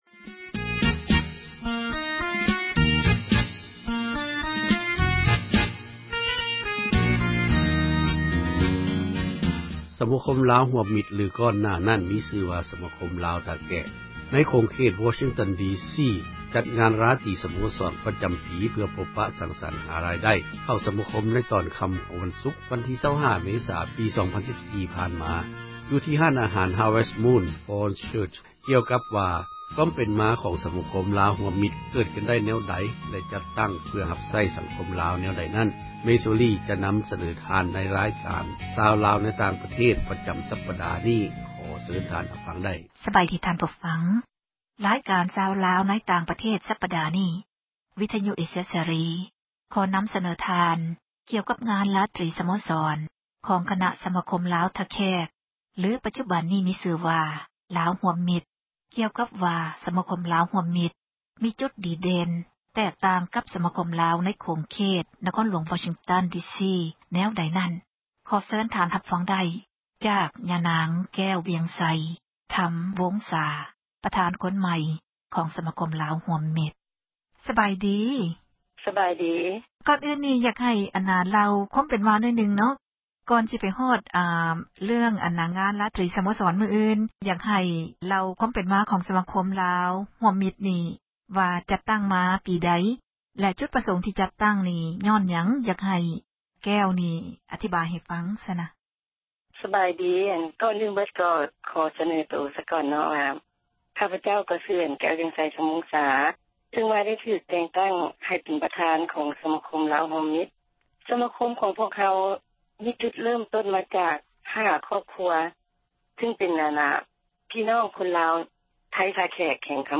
ການສໍາພາດສະມາຄົມ ລາວຮ່ວມມິດ